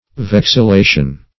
Search Result for " vexillation" : The Collaborative International Dictionary of English v.0.48: Vexillation \Vex`il*la"tion\, n. [L. vexillatio.]